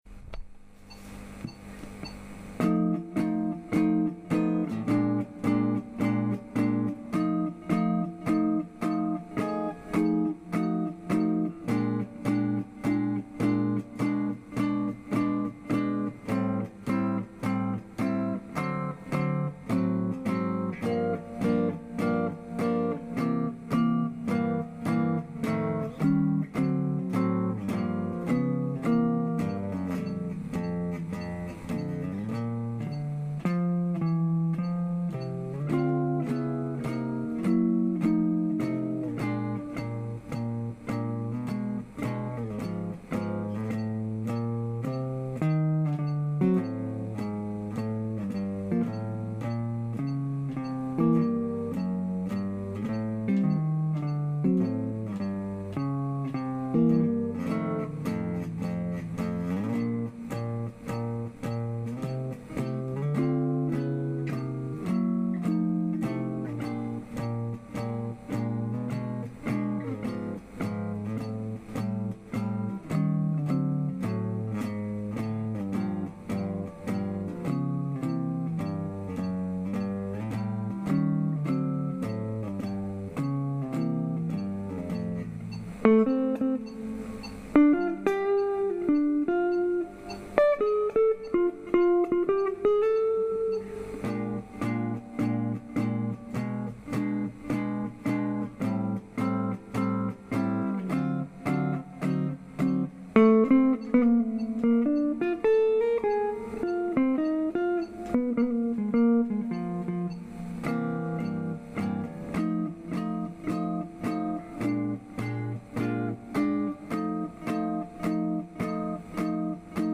ブルース
フォーバースとは4小節交代でアドリブをとること です。 ではブルースでテーマ・アドリブ・4バースの練習をしてみましょう。